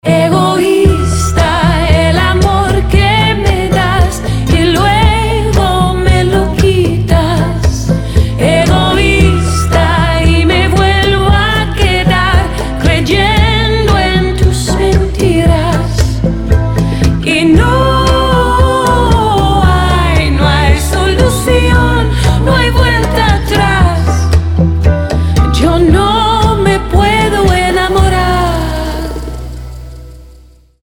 • Качество: 320, Stereo
поп
спокойные
чувственные
красивый женский вокал
латинские
Красивый чувственный рингтон на испанском языке